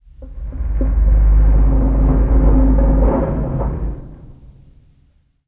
metal_low_creaking_ship_structure_05.wav